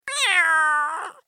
دانلود صدای گربه کوچک کارتونی از ساعد نیوز با لینک مستقیم و کیفیت بالا
جلوه های صوتی